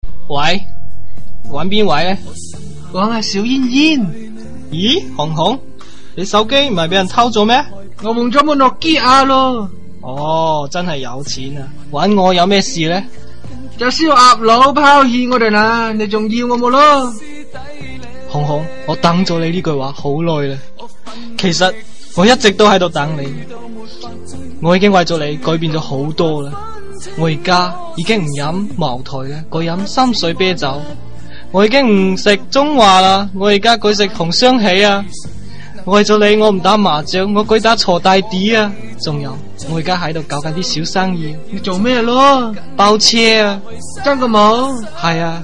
搞笑手机铃声